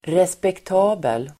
Uttal: [respekt'a:bel]